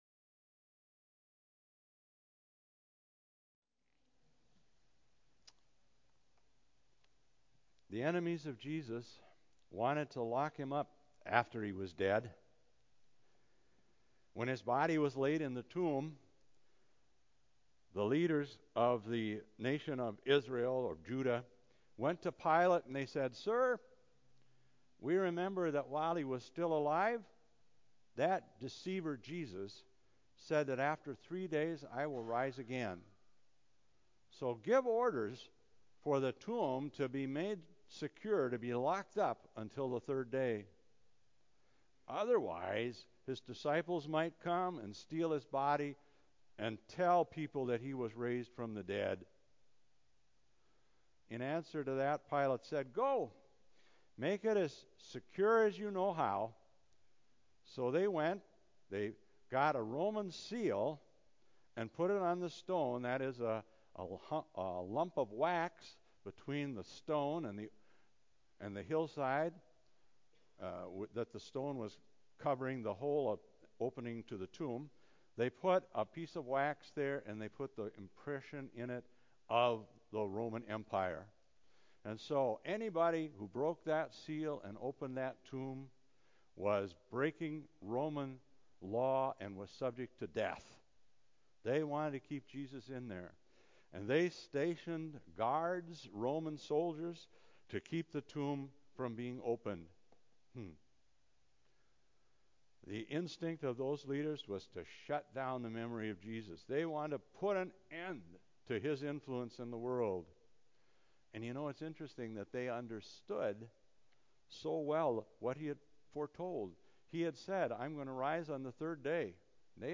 Church-Sermons-4.19.19-CD.mp3